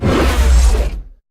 driveVehicle.ogg